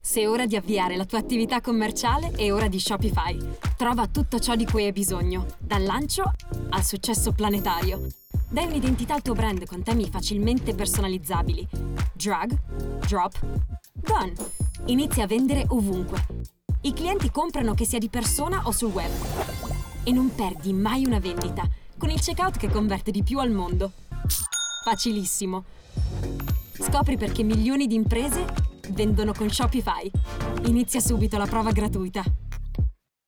Female
TEENS, 20s, 30s
Character, Friendly, Warm, Versatile, Young, Bubbly, Children, Approachable, Bright, Engaging
Microphone: SONTRONICS STC-20
Audio equipment: TASCAM interface / Soundproof studio / Connection: Skype/ Zoom/ Phone call/ Source Connect